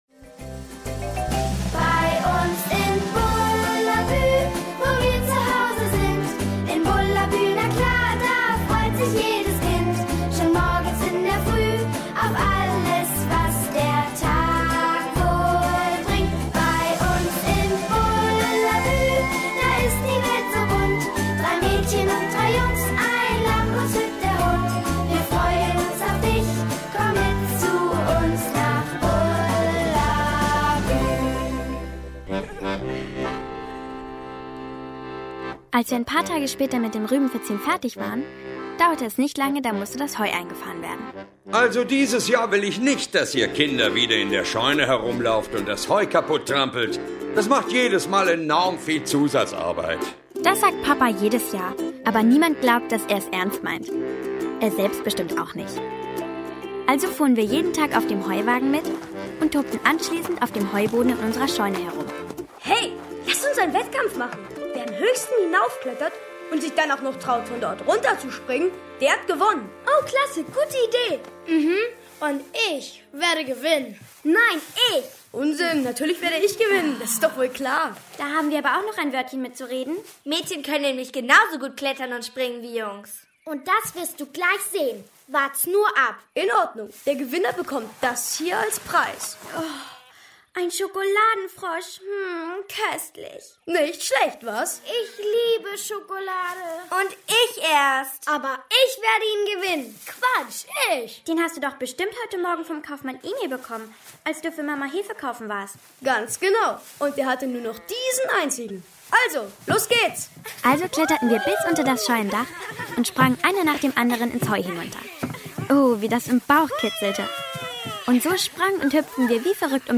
Wir Kinder aus Bullerbü 1 Das Hörspiel für Kinder ab 4 Jahren Astrid Lindgren (Autor) Dieter Faber , Frank Oberpichler (Komponist) Audio-CD 2013 | 9.